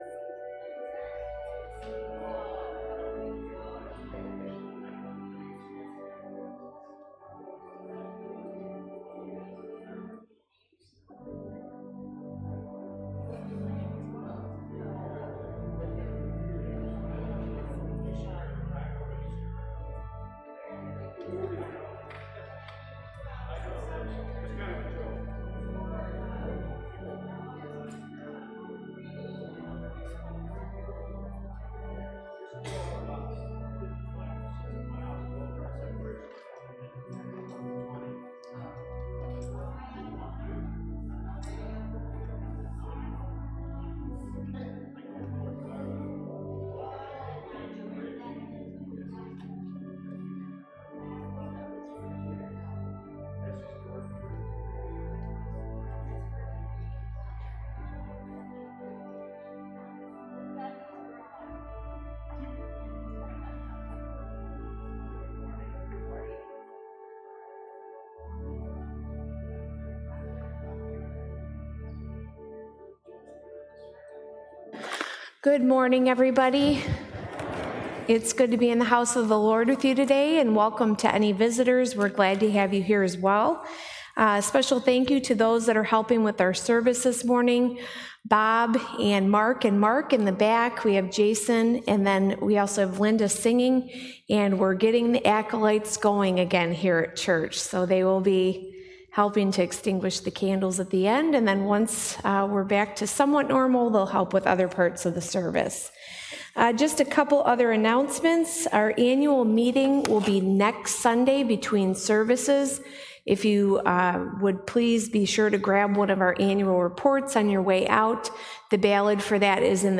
Passage: Isaiah 25, Philippians 4, Matthew 25 Service Type: Sunday Worship Service